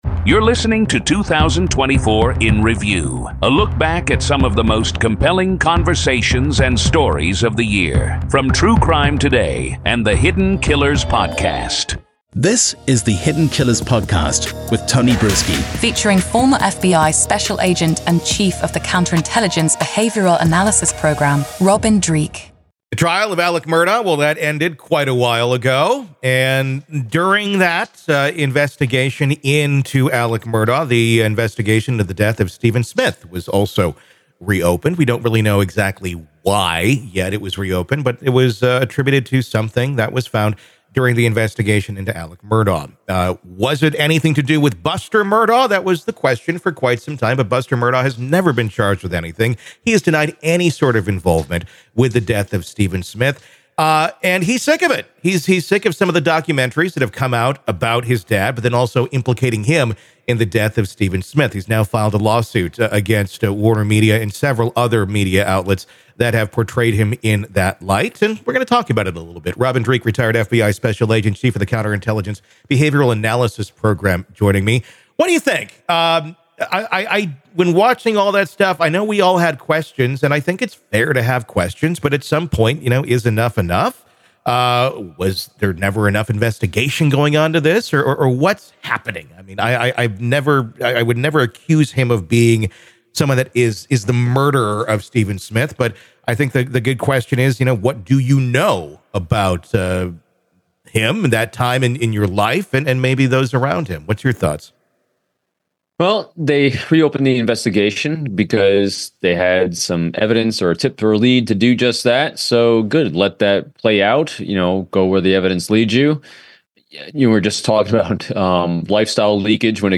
Each episode navigates through these stories, illuminating their details with factual reporting, expert commentary, and engaging conversation.